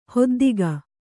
♪ hoddiga